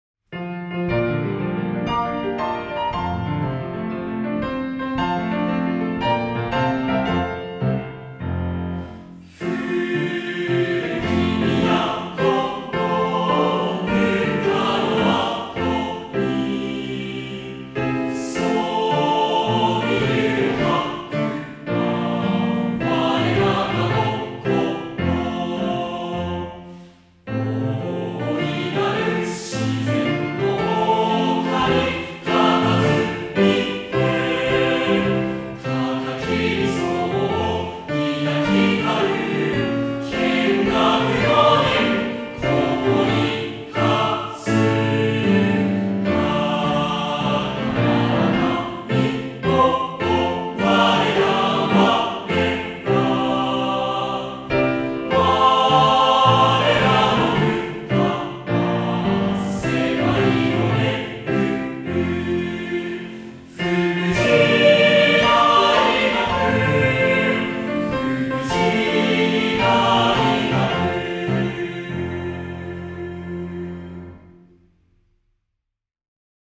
〇学歌(歌入･ﾋﾟｱﾉ)